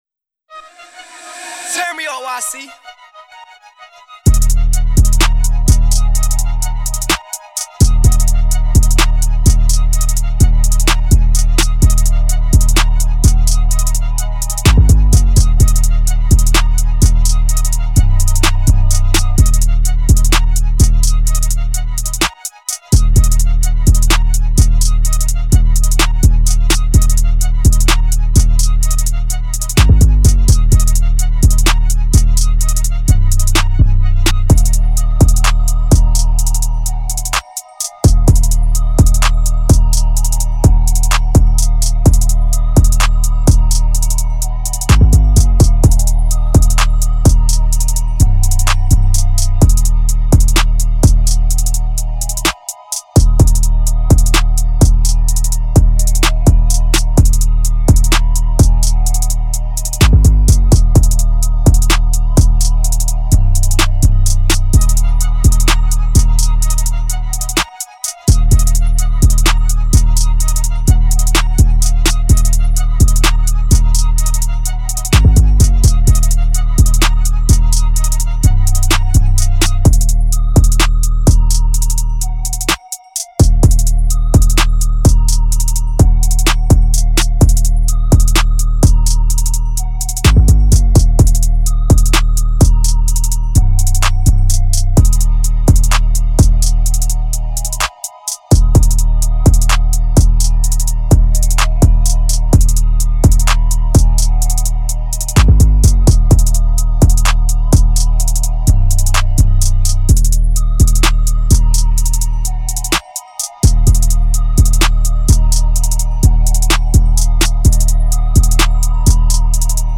Trap Instrumentals